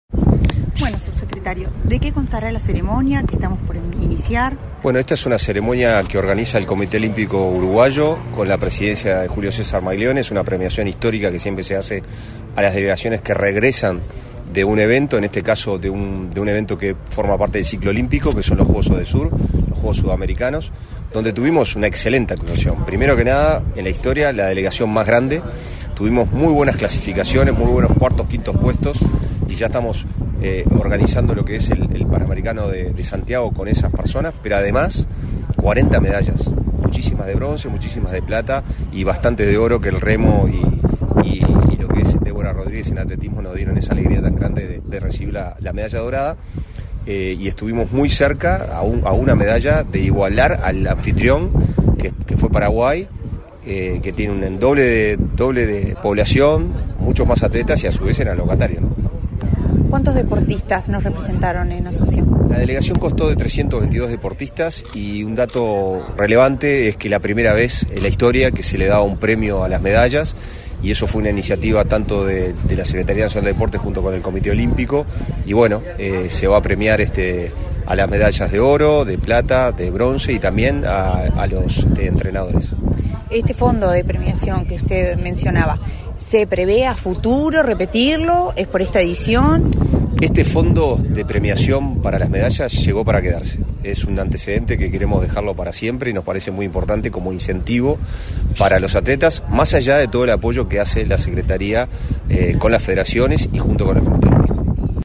Entrevista al subsecretario de la Secretaría Nacional del Deporte, Pablo Ferrari
Autoridades de la Secretaría Nacional del Deporte (SND) y del Comité Olímpico Uruguayo (COU) realizaron, este 11 de noviembre, la ceremonia de reconocimiento a la delegación de deportistas que participó de los Juegos Odesur. El subsecretario de la SND, Pablo Ferrari, antes del evento, realizó declaraciones a Comunicación Presidencial.